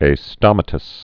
(ā-stŏmə-təs, ā-stōmə-) also as·tom·ous (ăstə-məs) or a·stom·a·tal (ā-stŏmə-təl, ā-stōmə-)